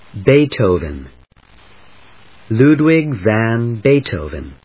音節Bee・tho・ven 発音記号・読み方/béɪtoʊv(ə)n‐t(h)əʊ‐/, Ludwig van /lúːdwɪg væn/発音を聞く